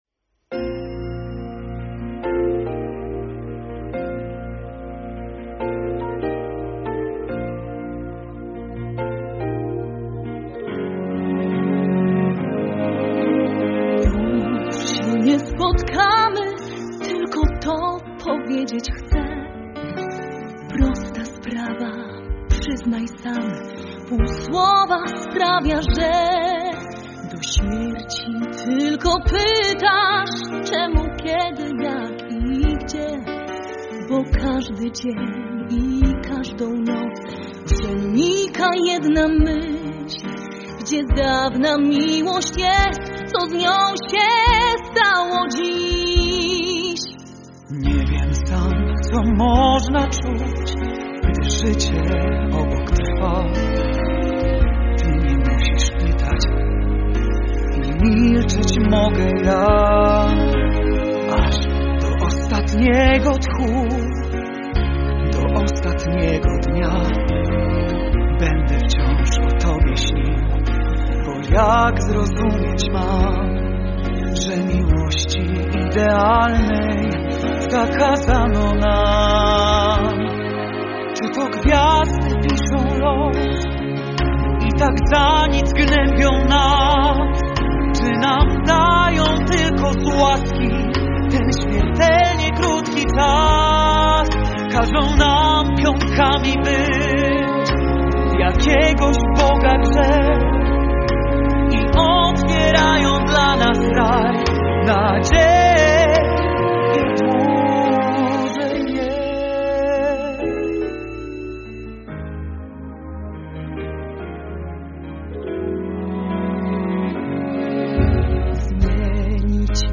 fortepian, instrumenty klawiszowe
gitary
gitara basowa
perkusja
instrumenty perkusyjne
skrzypce
altówka
wiolonczela
waltornia
flet, flet altowy
obój, rożek angielski